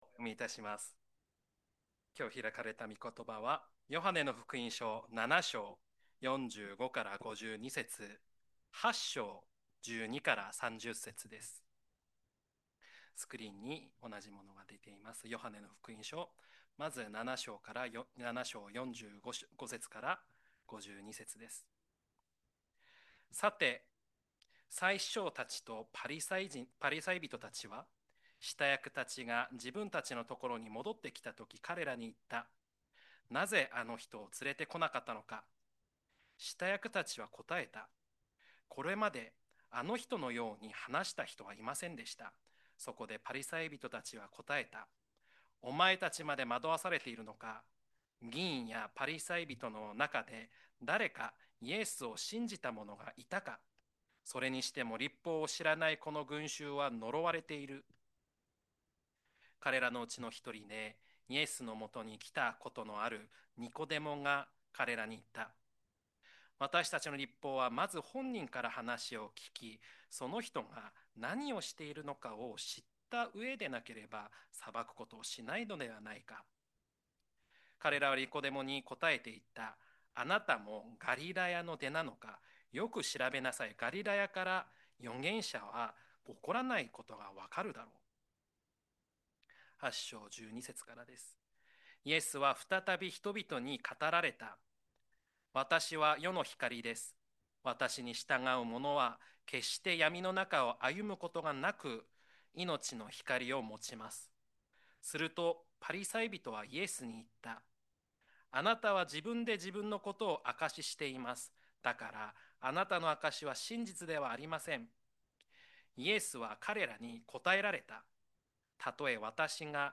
礼拝式順